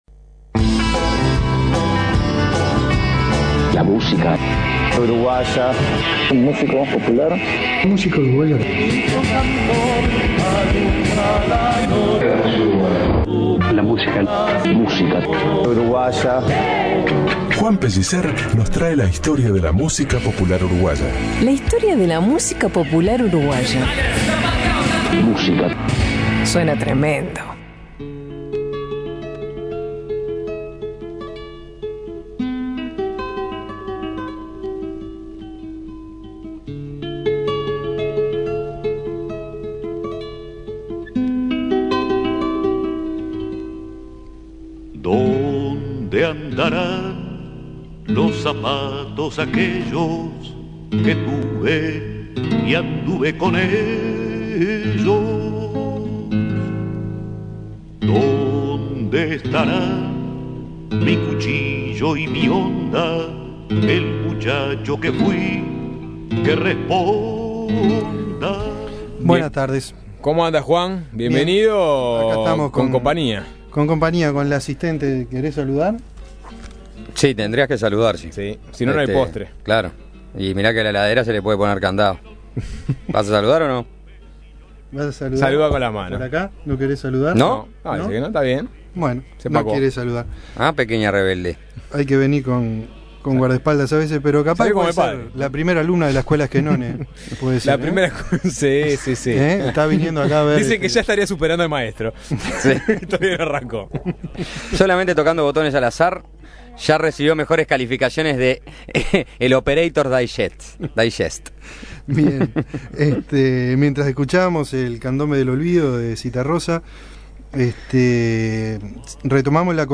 Testimonios de Pepe Guerra, Daniel Viglietti y El Sabalero, entre otros, nos ayudan a construir las vivencias de los artistas nacionales en ese contexto.